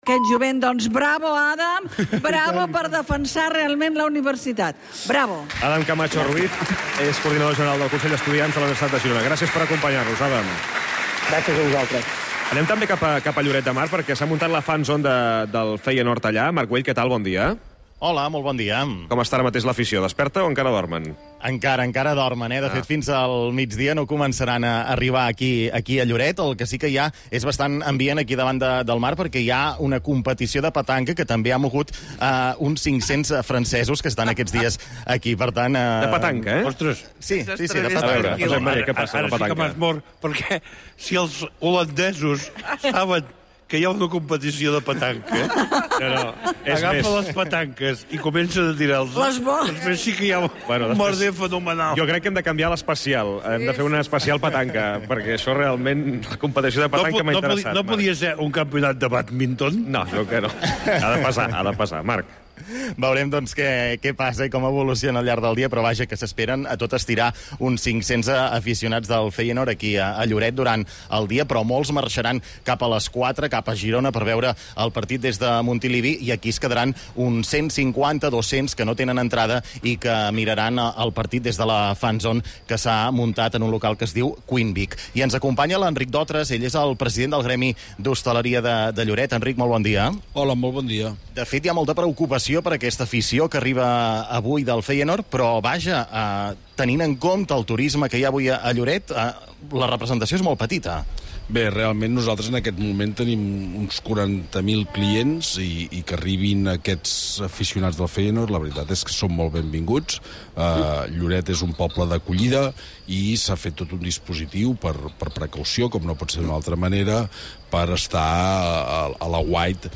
Hores abans del partit de Champions del Girona hem fet tertlia esportiva